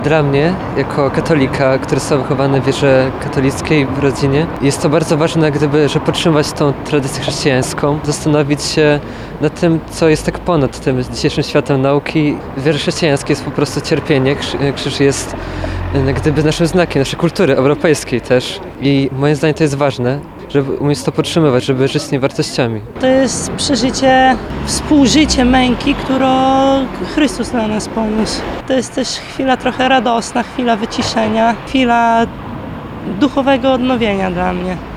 Nabożeństwo święta Podwyższenia Krzyża Świętego odbyło się w piątek (14.09.2018) w parafii pod wezwaniem Najświętszego Serca Pana Jezusa w Suwałkach.
– To chwila refleksji, duchowego odnowienia, symbol ważny dla naszej kultury – mówili Radiu 5 zebrani.